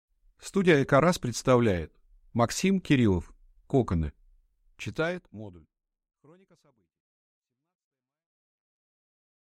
Аудиокнига Коконы | Библиотека аудиокниг